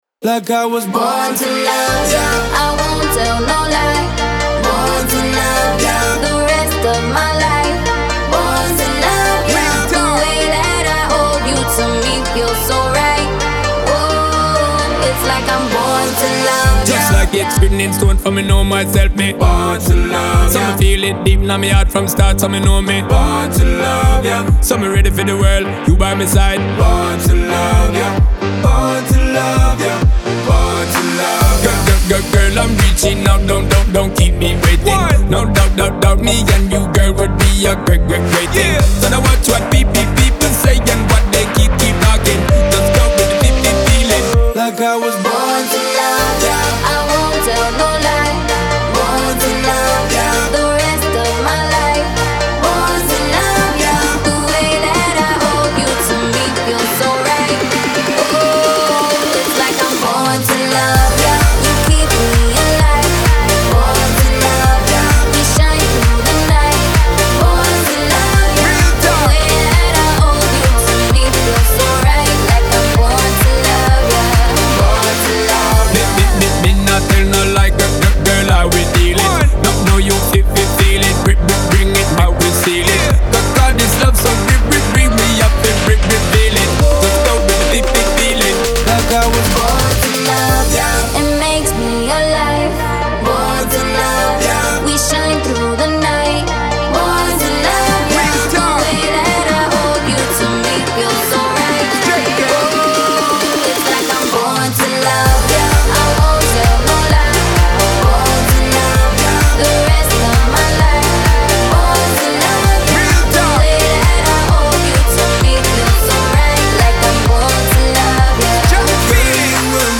آهنگ لاتین